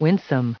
added pronounciation and merriam webster audio
816_winsome.ogg